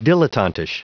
Prononciation du mot dilettantish en anglais (fichier audio)
Prononciation du mot : dilettantish